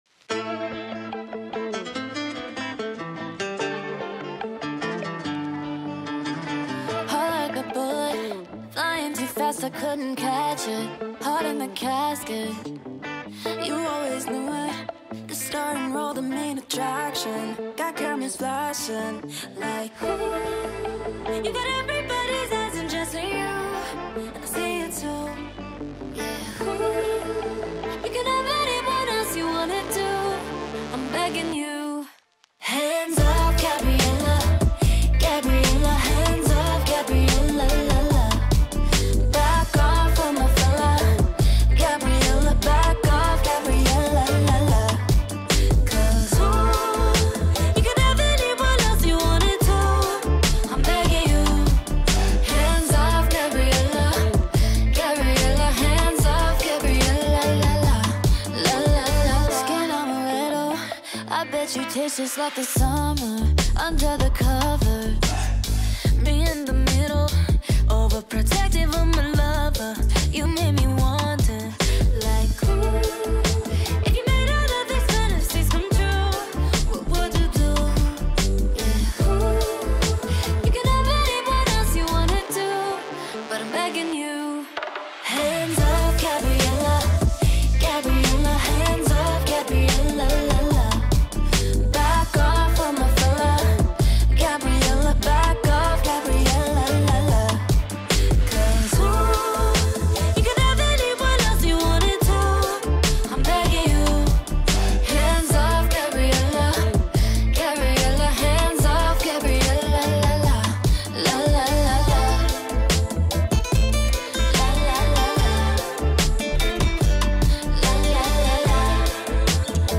Pop / Latin Pop / R&B